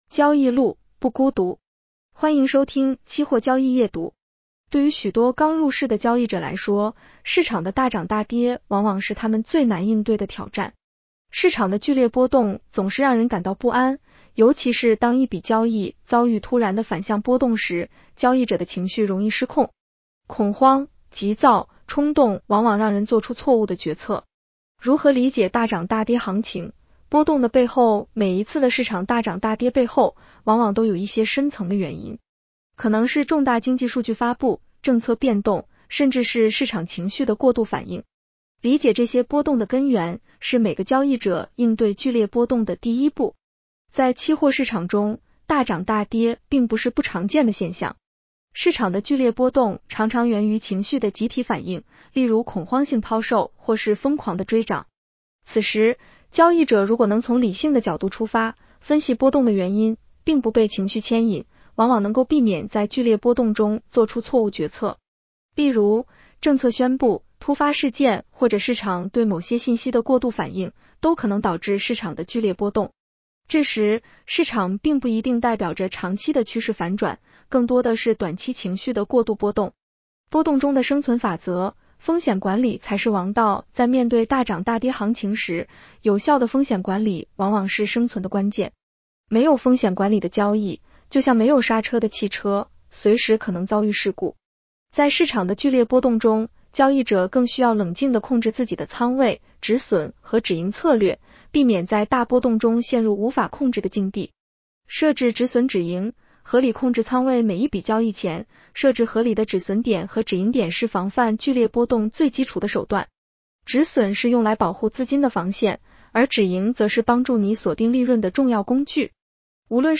女声普通话版 下载mp3 交易路，不孤独。